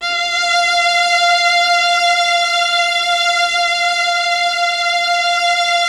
MELLOTRON.13.wav